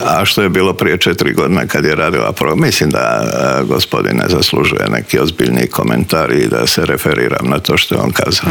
ZAGREB - Koji je najizgledniji datum za parlamentarne izbore, kakva su očekivanja HDZ-a, kao nositelj liste u šestoj izbornoj jedinici želi li još jedan mandat na čelu MUP-a, odgovore smo u Intervjuu tjedna Media servisa potražili od potpredsjednika Vlade i ministra unutarnjih poslova Davora Božinovića, koji nam prokomentirao i Zakon o strancima, ali i kako stojimo s ilegalnim migracijama.